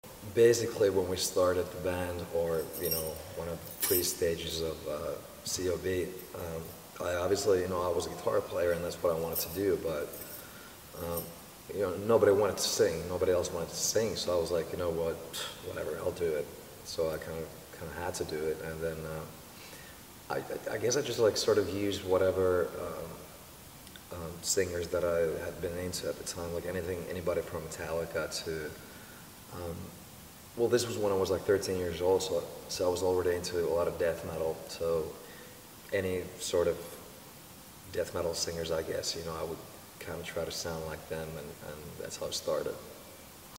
We found an old interview with Alexi Laiho from Children Of Bodom explaining how he found his singing voice 🖤